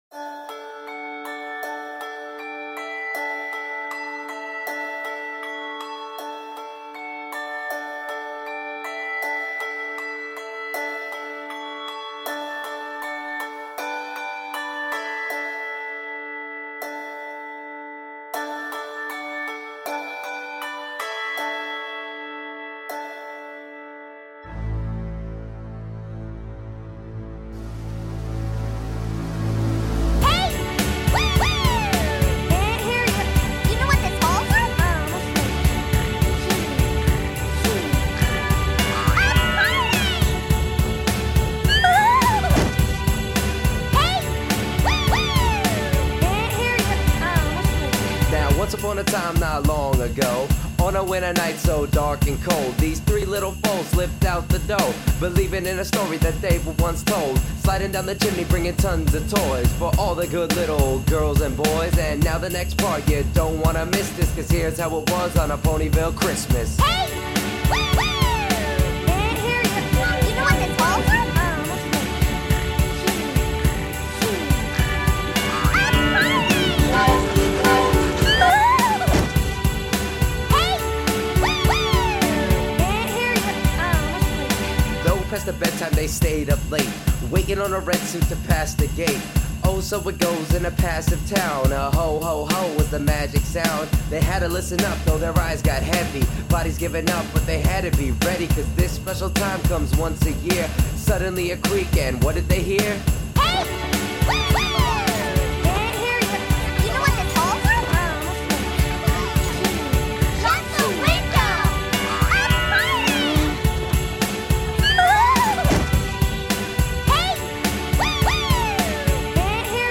Its super simple and super cheesy, you have been warned :)